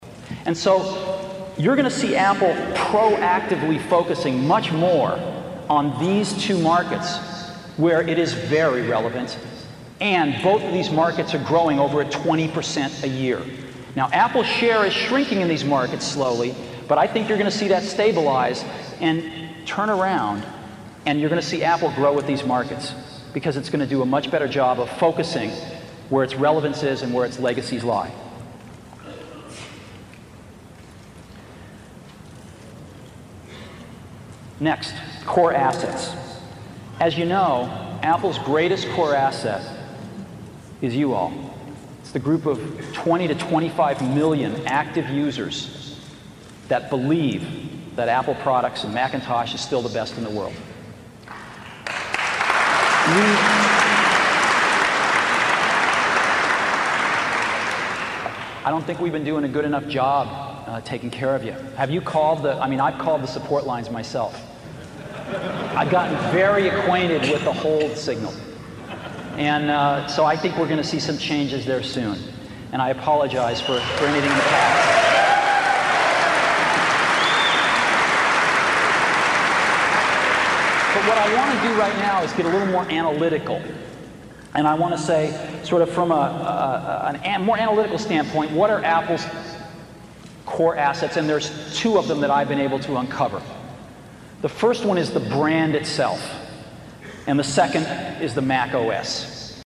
财富精英励志演讲87:在疯狂中我们看到了天才(7) 听力文件下载—在线英语听力室